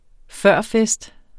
Udtale [ ˈfɶˀɐ̯ˌfεsd ]